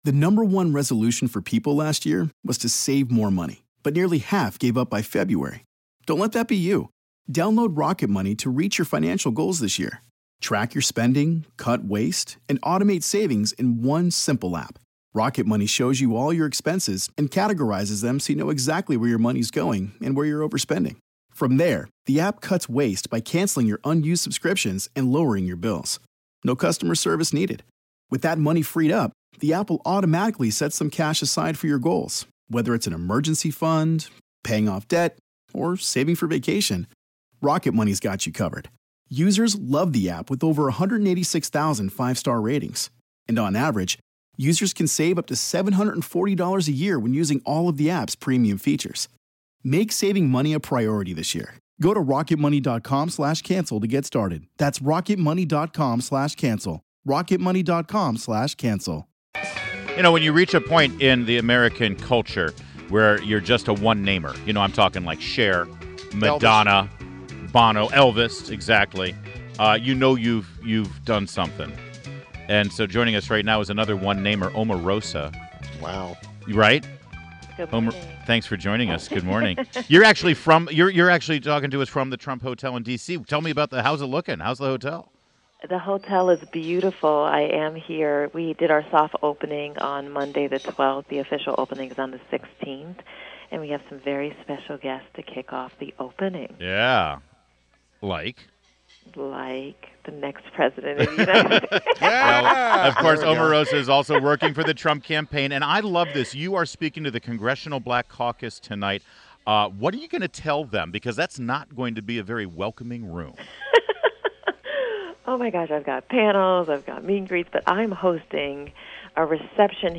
WMAL Interview - OMAROSA - 09.15.16
INTERVIEW — OMAROSA MANIGAULT — former contestant on Donald Trump’s The Apprentice show and now director of African-American outreach for Trump’s presidential campaign